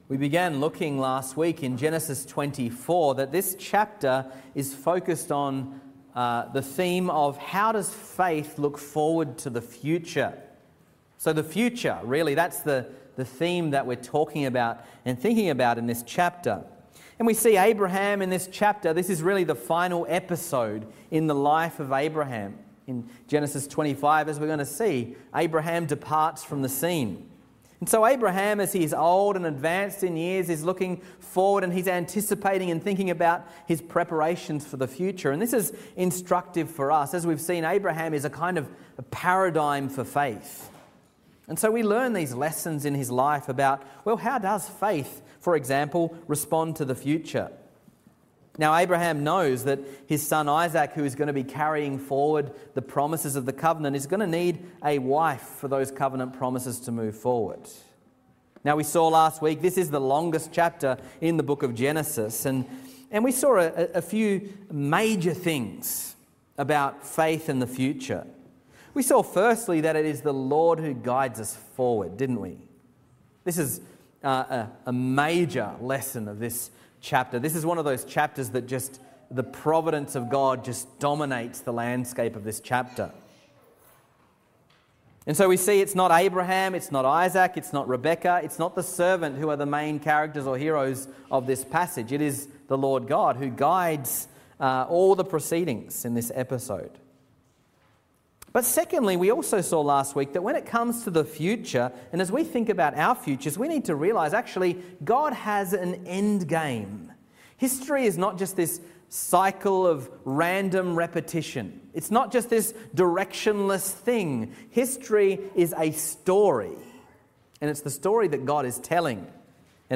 Sermons | Reformed Church Of Box Hill
Morning Service